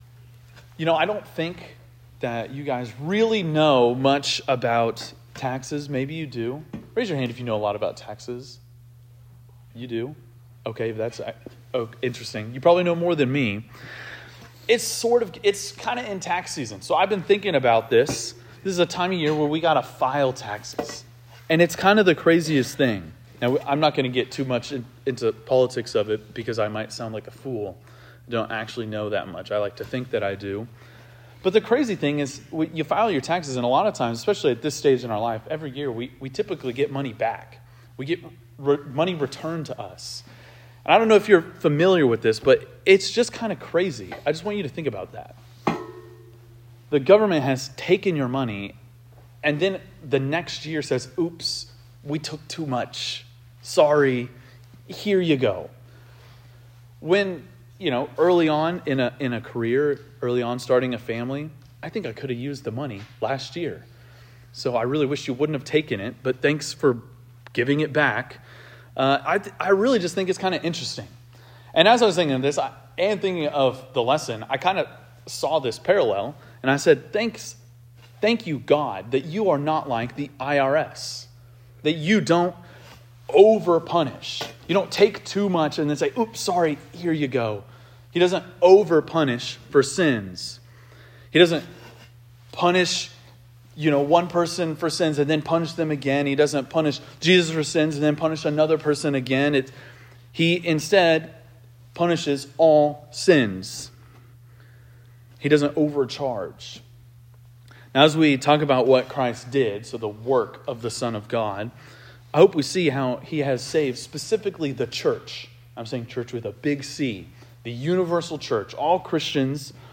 teaches on the topic of the Son's work, focusing on the nature and extent of the atonement.&nbsp